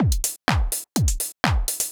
Midlands Beat_125.wav